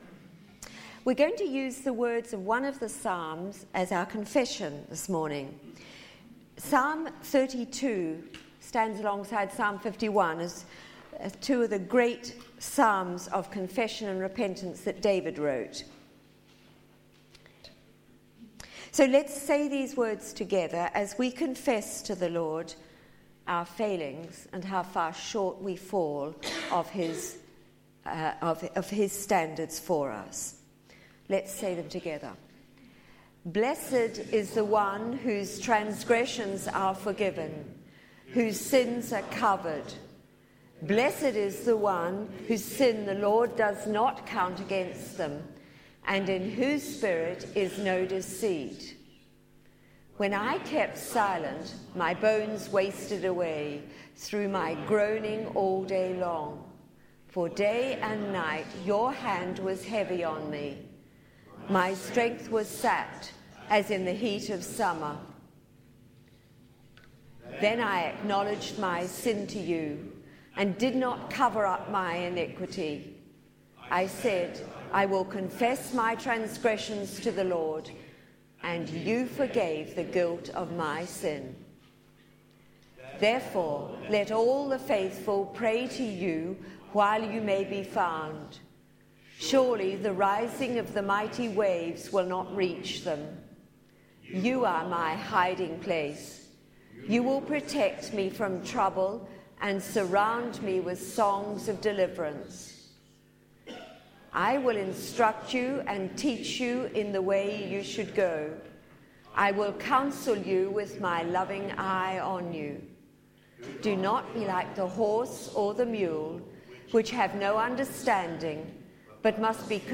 Media for Sunday Service on Sun 04th Oct 2015 11:00
Theme: Sermon